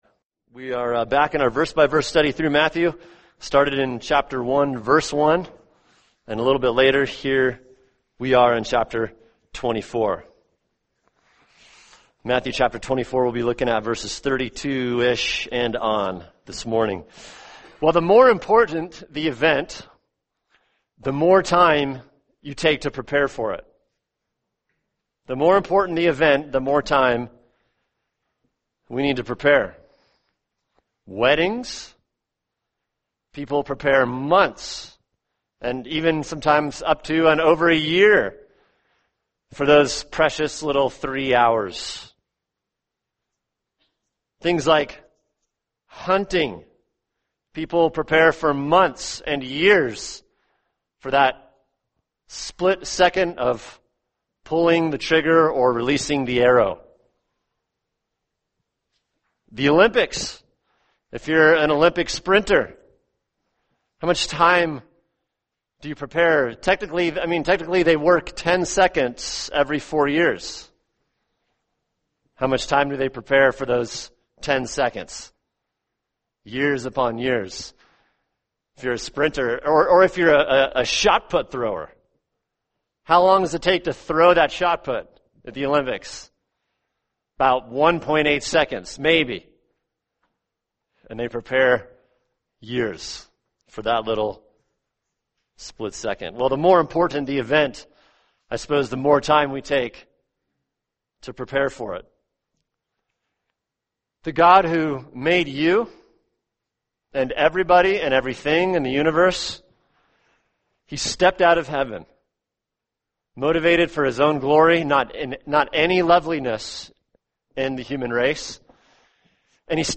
[sermon] Matthew 24:32-41 The End Times: Be Ready, Part 1 | Cornerstone Church - Jackson Hole